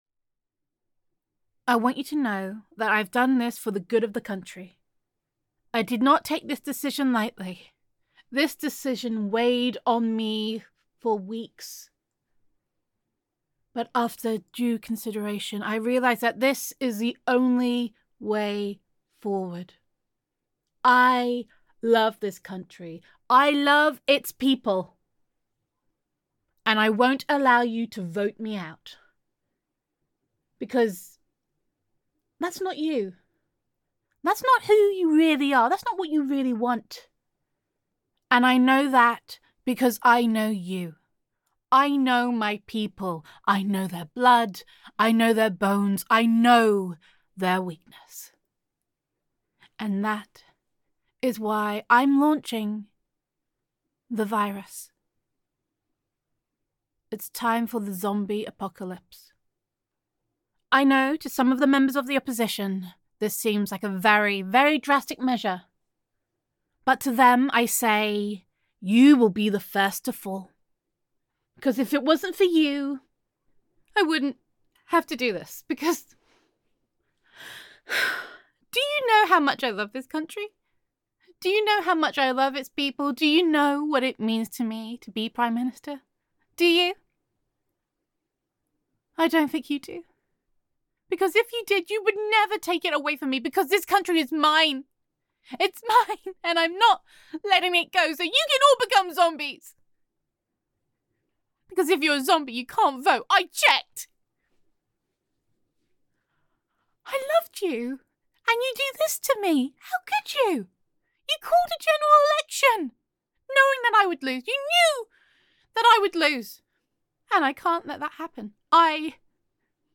[F4A] I Won’t Let Go [Party Political Broadcast][Drastic Action][Zombie Apocalypse][if You Won’t Love Me Then You Can Burn][Gender Neutral][A Prime Minister Really Does Not Like Losing]